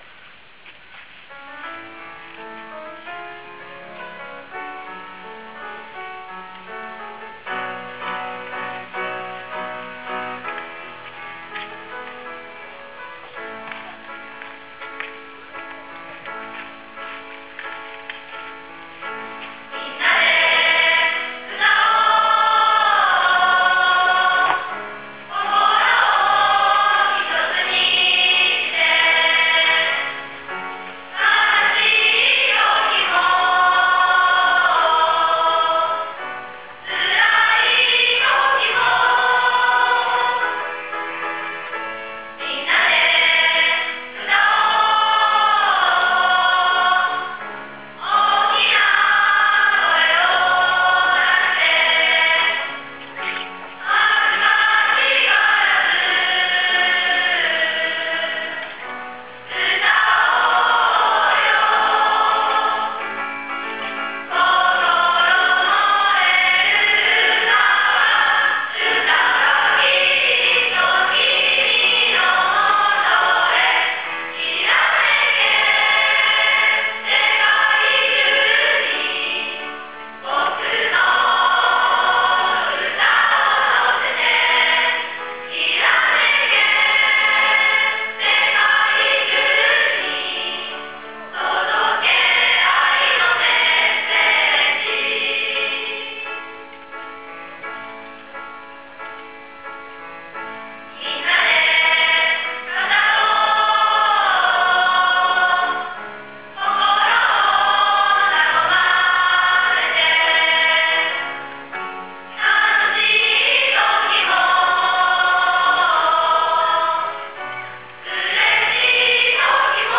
♪ とどけ愛のメッセージ ♪＜１年生全員合唱＞
素敵な歌詞に思いを乗せて素晴らしいハーモニーを響かせてくれました。